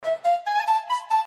• Качество: 320, Stereo
без слов
Флейта